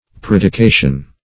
Predication \Pred`i*ca"tion\, n. [L. praedicatio: cf. F.